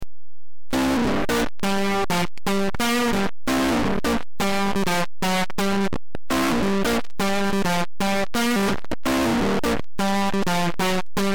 The following are mp3 samples that showcase different settings of Danstortion.
Guitar
Guitar_pos_neg.mp3